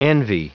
Prononciation du mot envy en anglais (fichier audio)
Prononciation du mot : envy